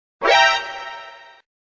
alert.mp3